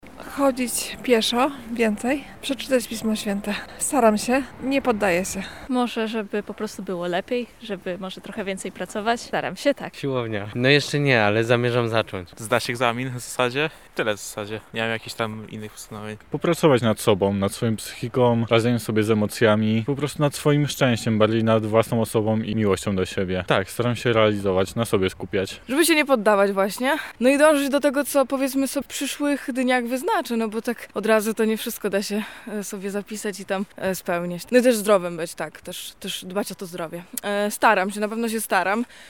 [SONDA] Z czym Lublinianie wchodzą w nowy rok?
Zapytaliśmy się mieszkańców Lublina o ich plany, jak i realizację:
Sonda postanowienia noworoczne